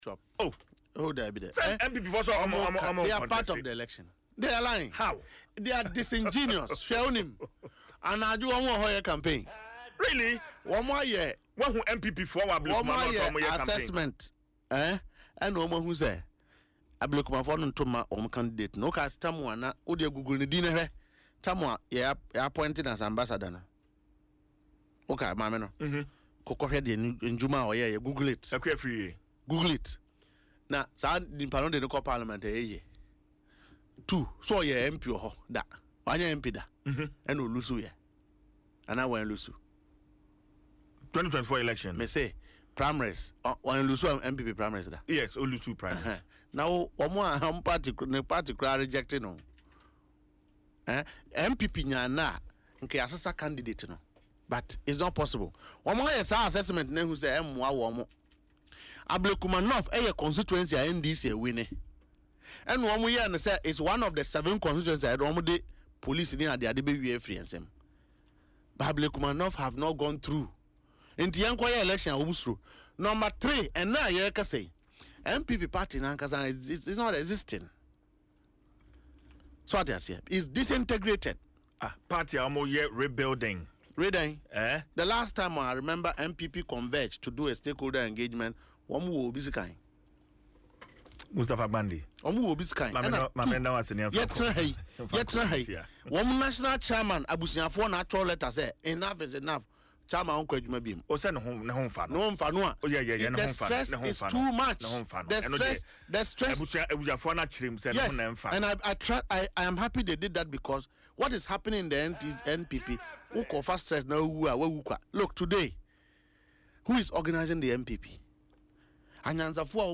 Speaking in an interview on Adom FM’s Dwaso Nsem